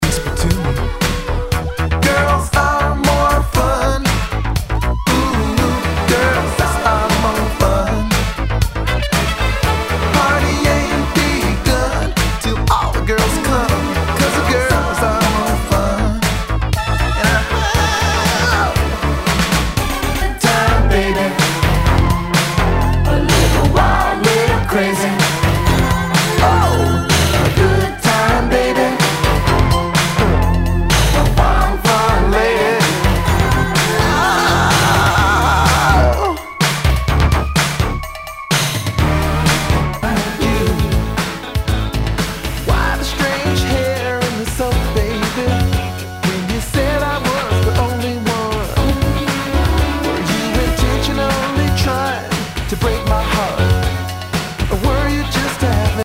SOUL/FUNK/DISCO
ナイス！シンセ・ポップ・ディスコ！